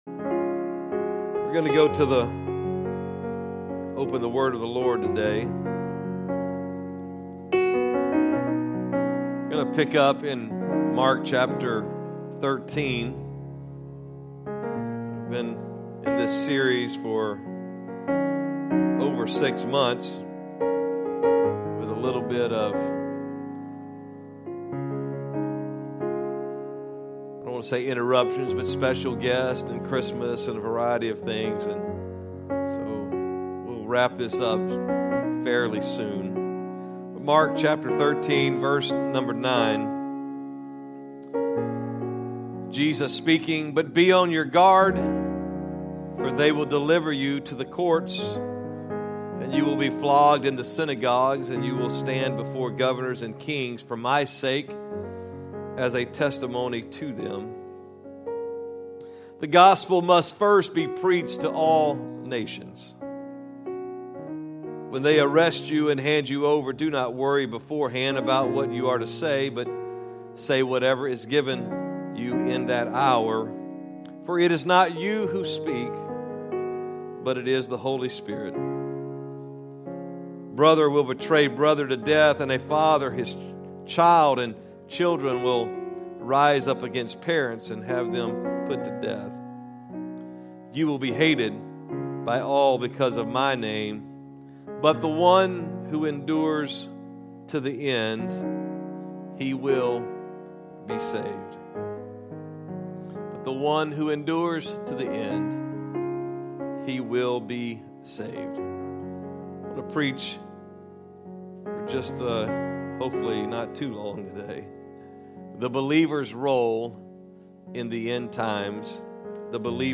Sermons | Cross Church Kansas City